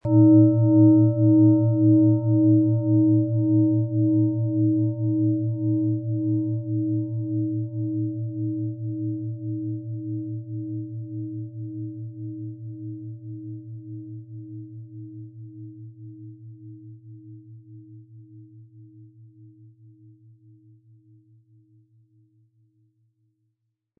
Es ist eine von Hand getriebene Klangschale, aus einer traditionellen Manufaktur.
• Tiefster Ton: Mond
• Höchster Ton: Mars
Sie möchten den Original-Ton der Schale hören? Klicken Sie bitte auf den Sound-Player - Jetzt reinhören unter dem Artikelbild.
PlanetentöneEros & Mond & Mars (Höchster Ton)
MaterialBronze